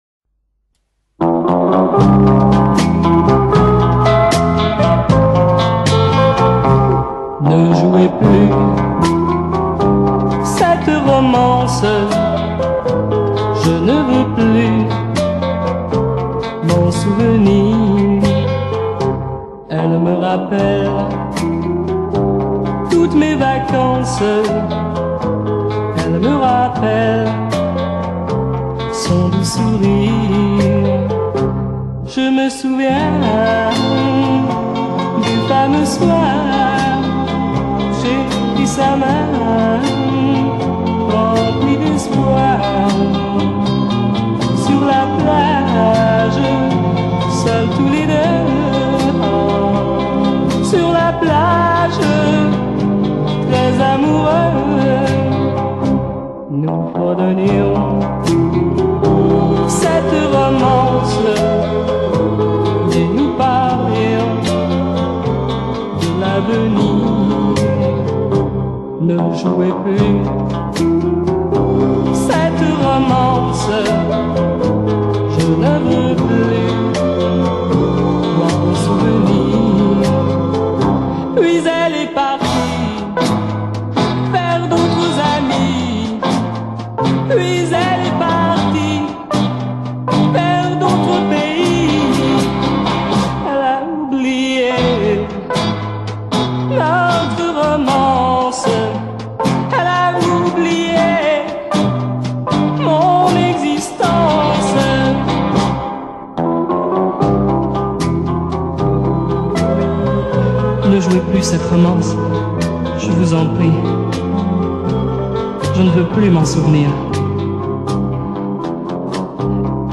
un autre chanteur yéyé